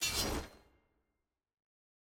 sfx_ui_map_panel_battle.ogg